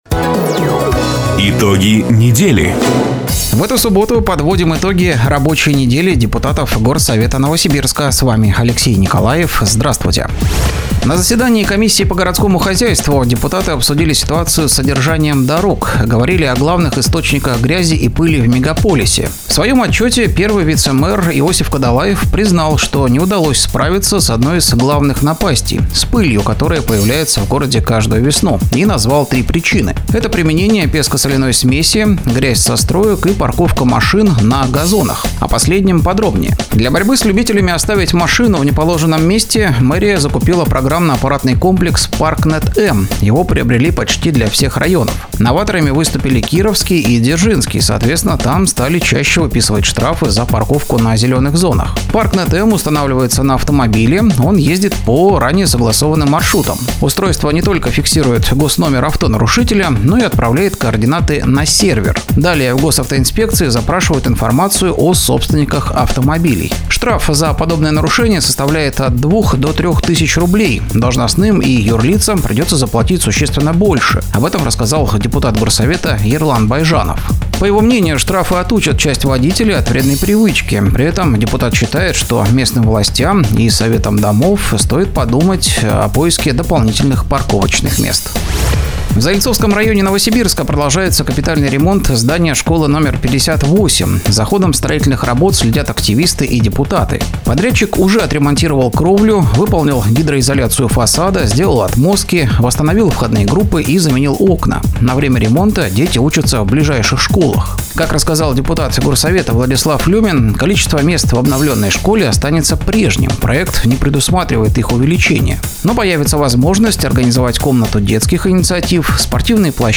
Запись программы "Итоги недели", транслированной радио "Дача" 26 апреля 2025 года